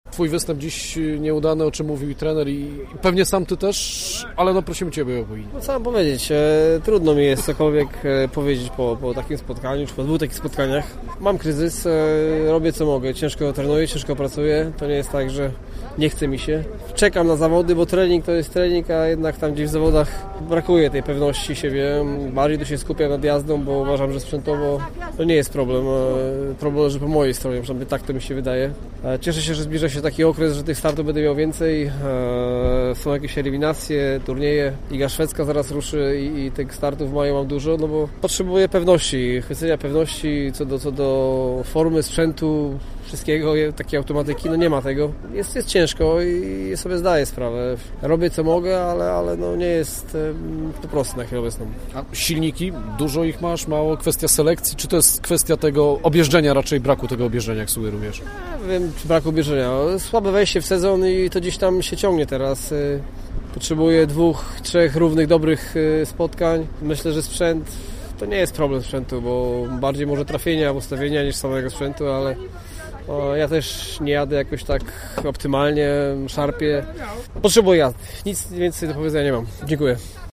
Posłuchajcie rozmowy z Piotrem Protasiewiczem: